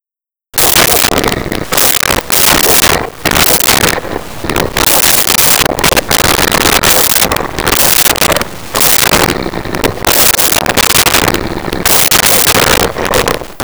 Dog Large Barking
Dog Large Barking.wav